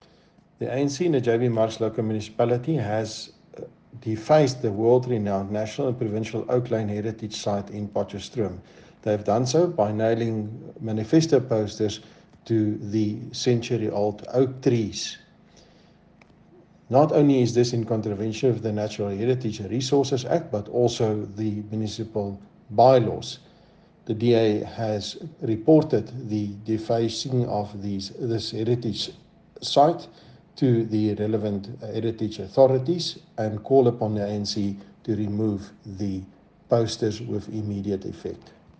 Issued by Cllr Hans-Jurie Moolman – DA Councillor: JB Marks Local Municipality
Note to Broadcasters: Please find linked soundbites in